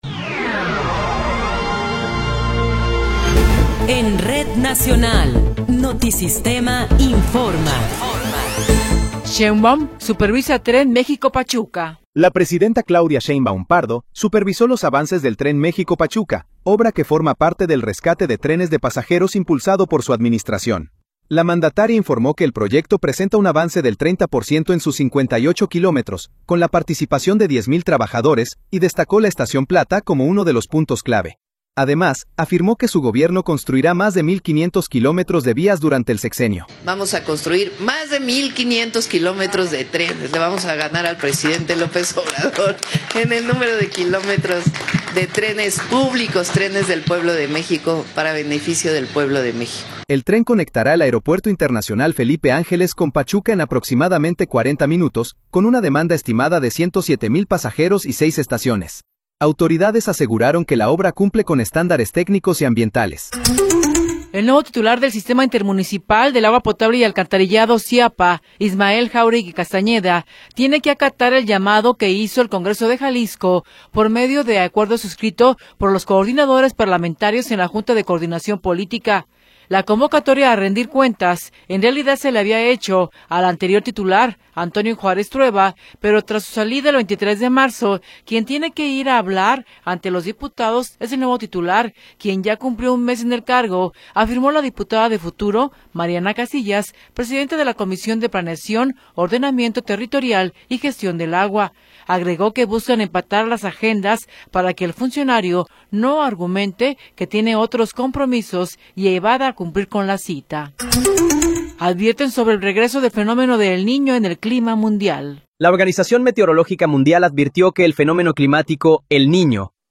Noticiero 19 hrs. – 24 de Abril de 2026
Resumen informativo Notisistema, la mejor y más completa información cada hora en la hora.